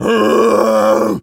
gorilla_growl_01.wav